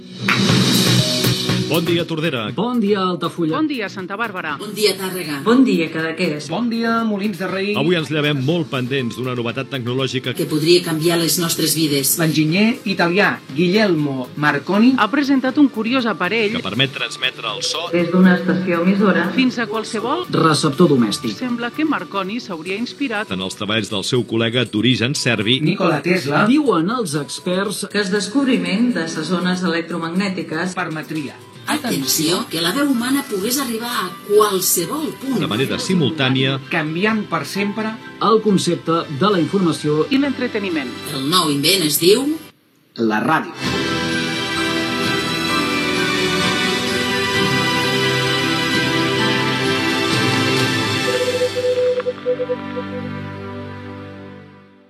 Espai promocional conjunt per celebrar el Dia Mundial de la Ràdio del 13 de febrer de 2022 fet per Ràdio Tordera, Altafulla Ràdio, Ràdio Santa Bàrbara, Ràdio Tàrrega, Ràdio Cap de Creus (Cadaqués) i Ràdio Molins de Rei